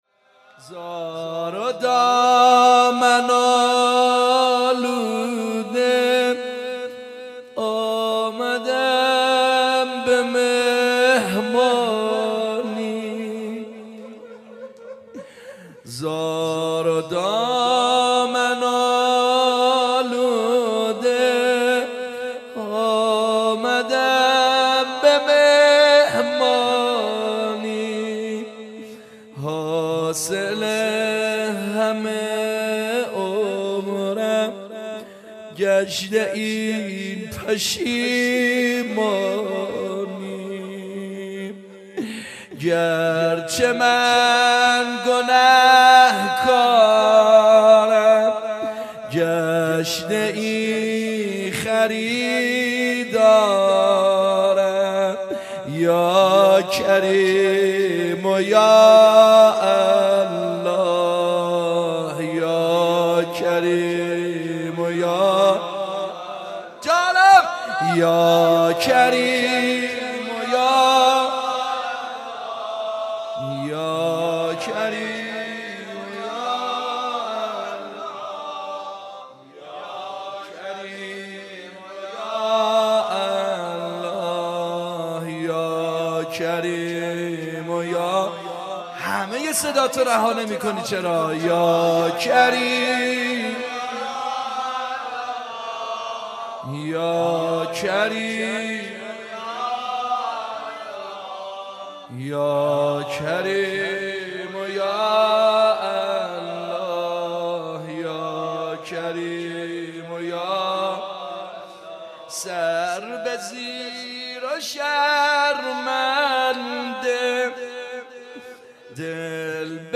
monajat.mp3